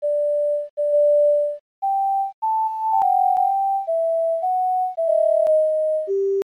all_i_want_sine_phrase_v4.mp3